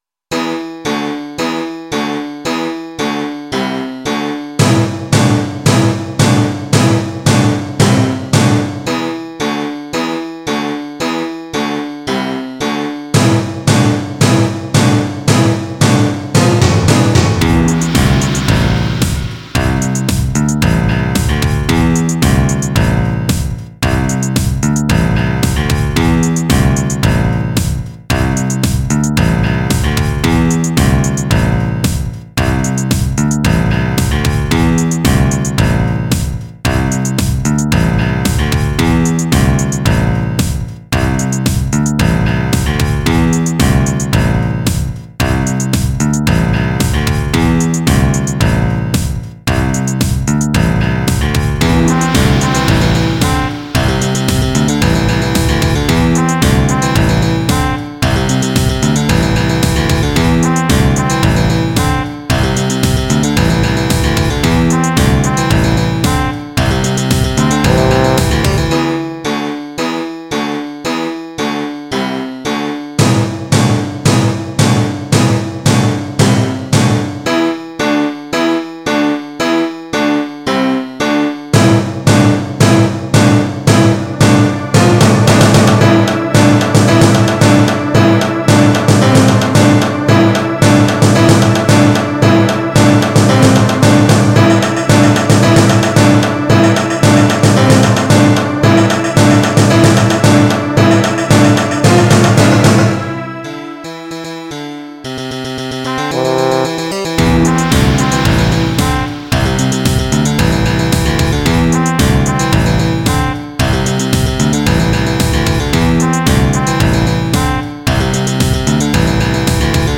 Žánr: Electro/Dance
Jeden motiv, tři různá aranžmá.